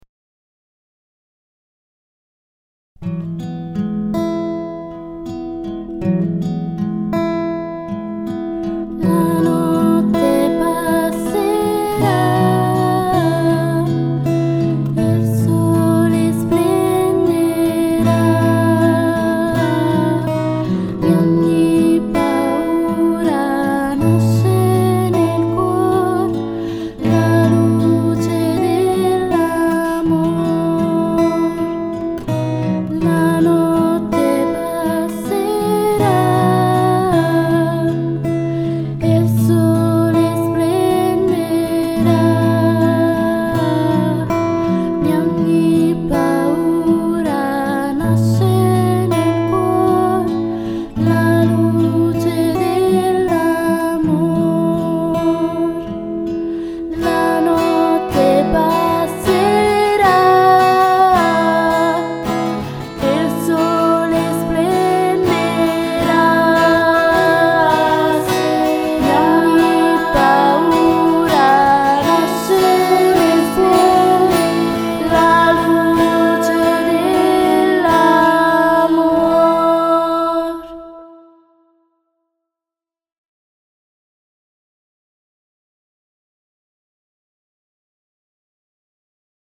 Filastrocca